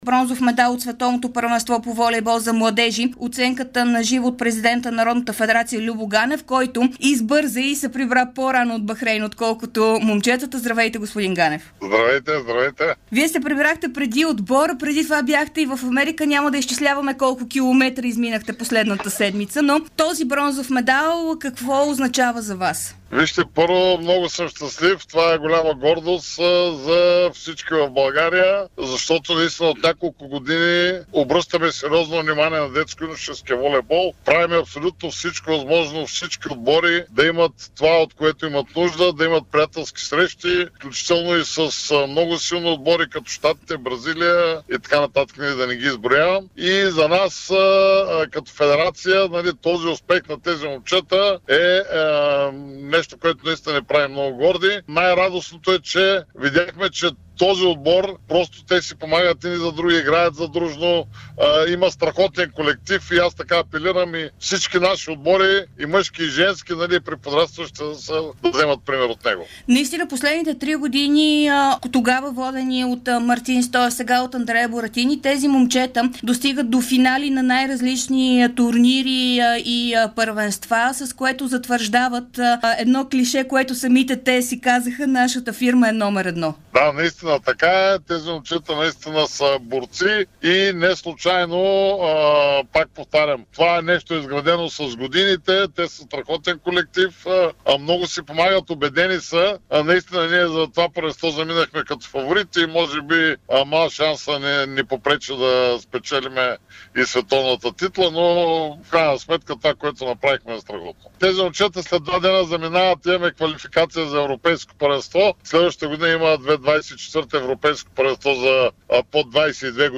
Президентът на българската федерация по волейбол Любо Ганев даде специално интервю пред Дарик радио, в което говори за третото място, завоювано от националния отбор на България от Световното първенство в Бахрейн до 21 години, както и за това, което предстои пред останалите ни национални гарнитури по волейбол.